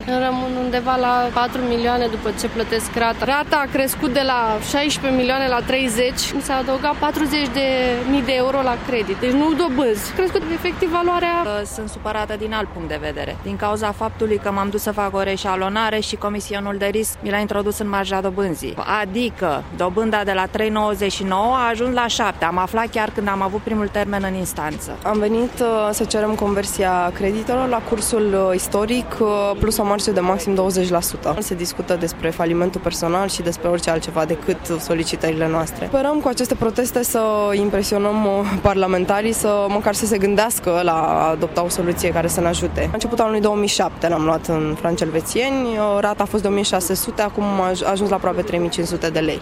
Cateva zeci de bucuresteni protesteaza si astazi in fata Camerei Deputatilor pentru a obtine conversia creditelor din franci elvetieni in moneda nationala.